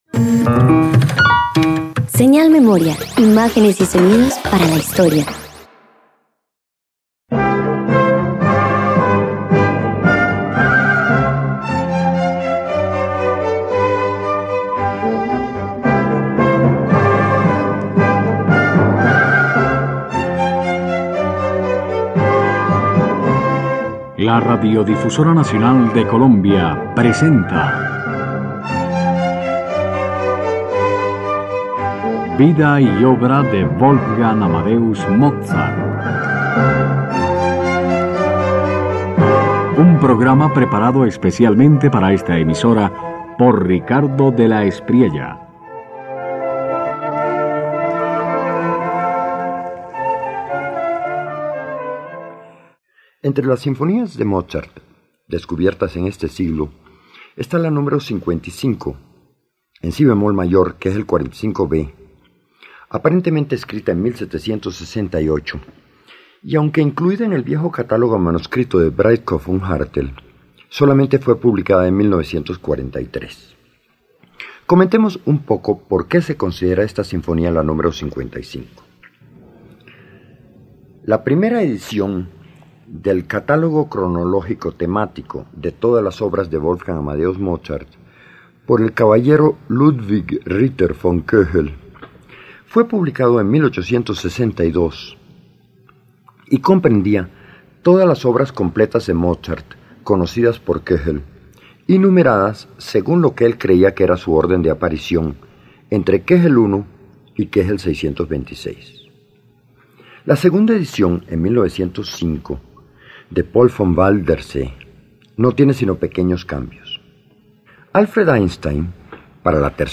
No persigue el efecto espectacular, sino una continuidad natural donde cada motivo se convierte en conversación.